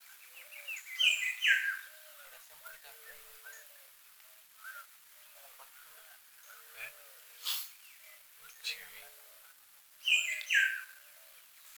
Japiim-preto (Cacicus solitarius)
Nome em Inglês: Solitary Cacique
Detalhada localização: Reserva privada Don Sebastián
Condição: Selvagem
Certeza: Observado, Gravado Vocal
Boyero-negro--1-_1.mp3